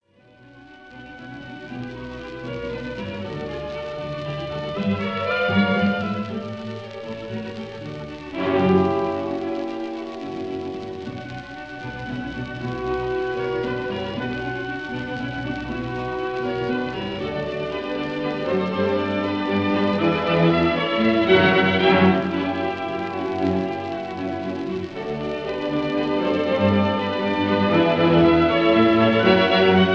Recorded in 1933